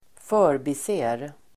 Uttal: [²fö:rbi:se:r]